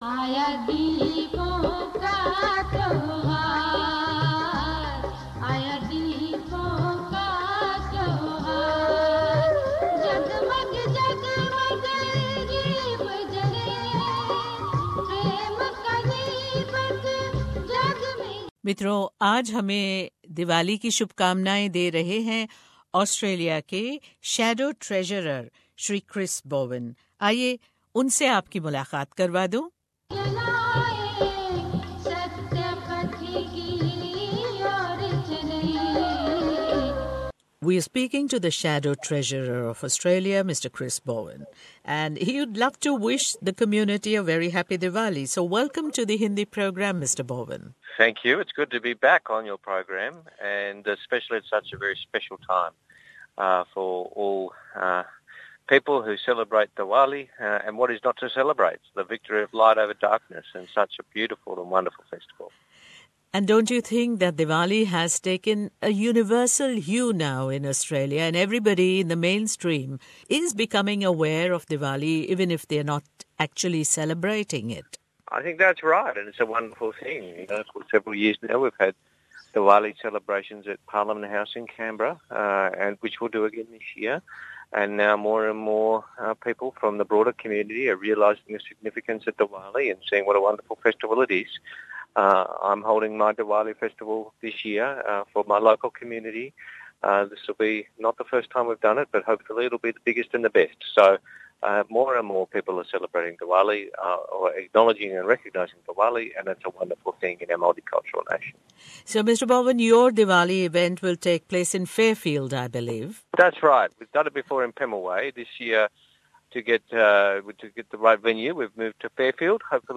Chris Bowen gives a special message for the Indian Community celebrating Diwali in Australia.